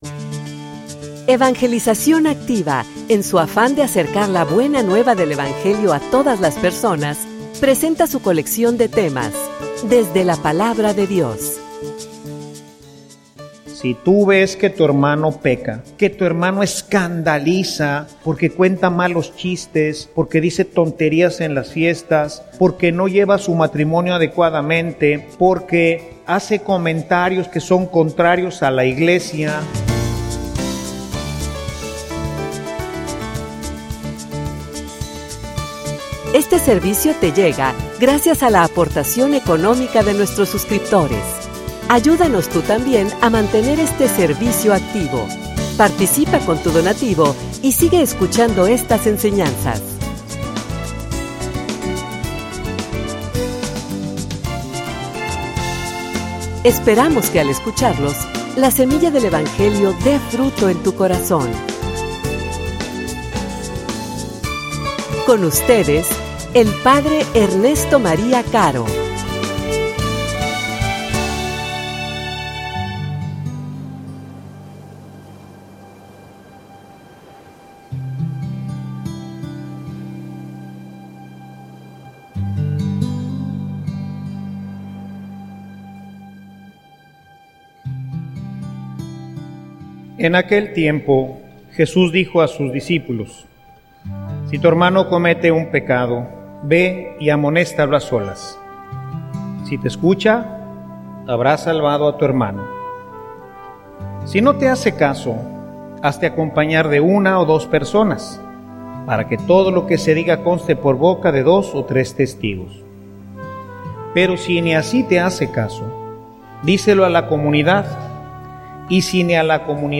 homilia_El_escandalo.mp3